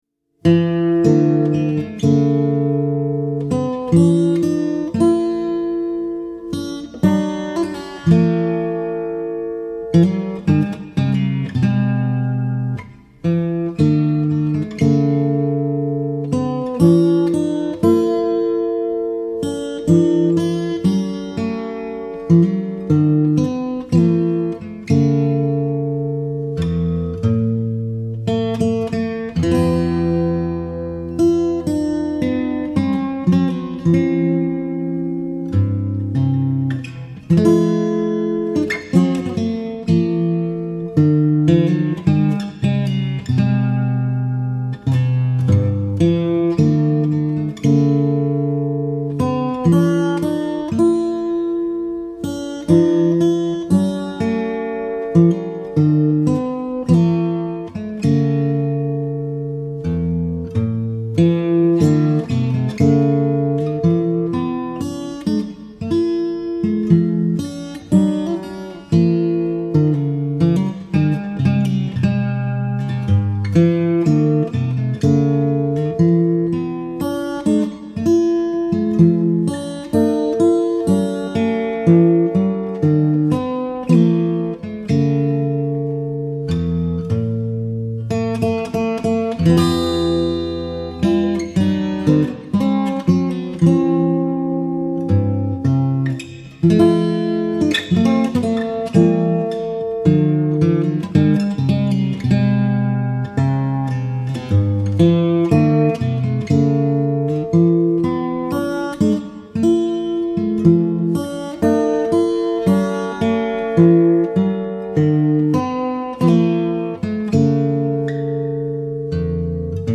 FINGERPICKING SOLO
Guitar Solos
Dropped D tuning DADGBE